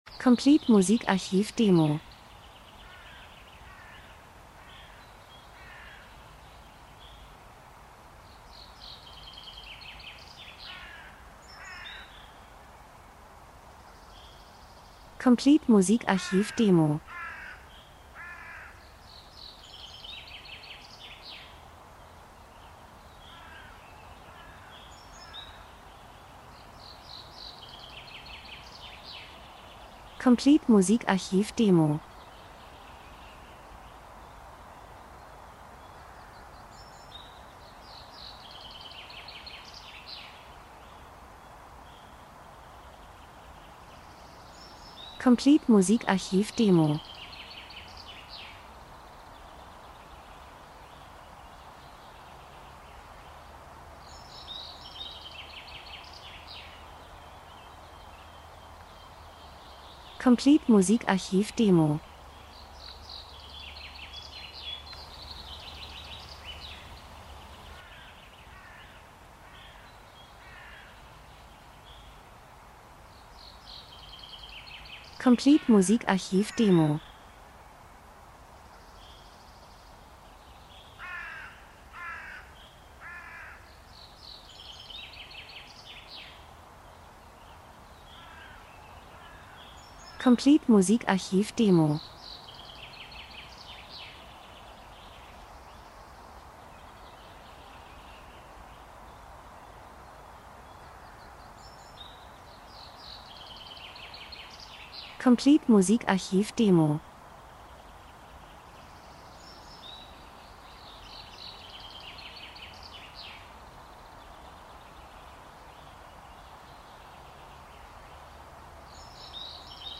Frühling -Geräusche Soundeffekt Natur Vögel Wind Raben 02:14